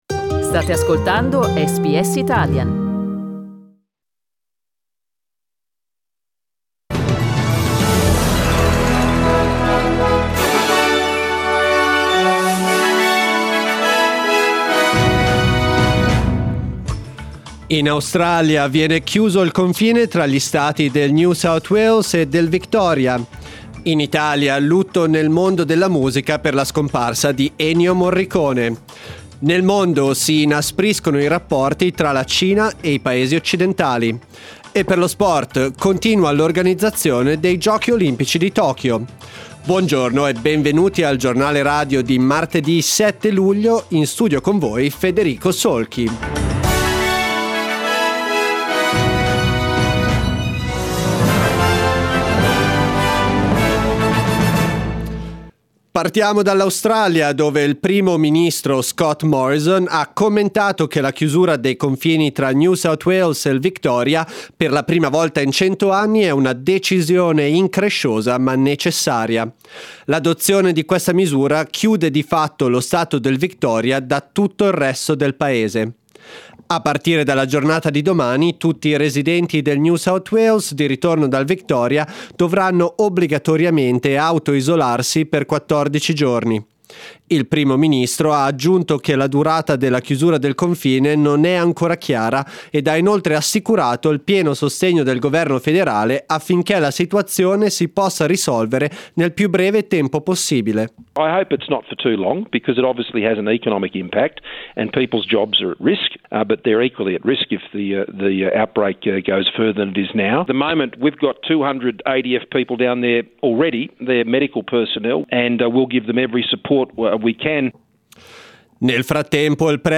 Our news bulletin (in Italian)